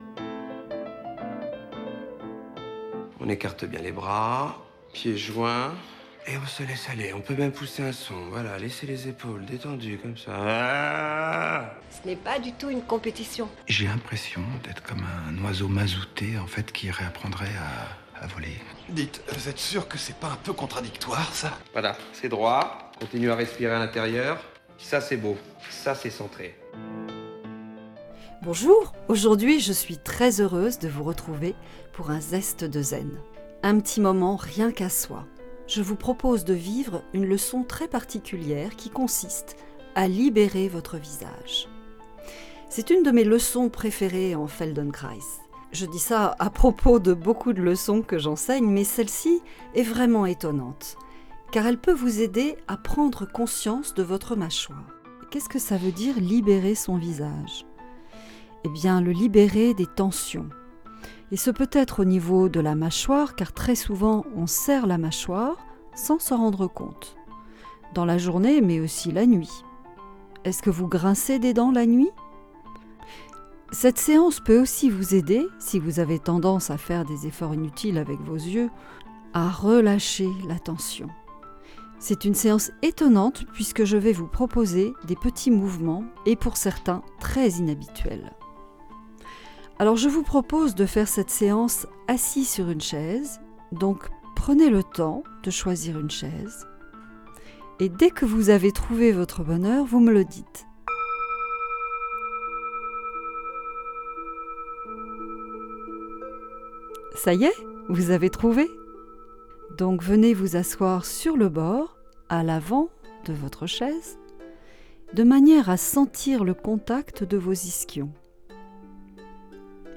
Laissez-vous guider, sans effort et profitez de l'instant et de cette découverte de votre corps, de vos postures et de vos gestes. Pour bien terminer l'année, on libère son visage !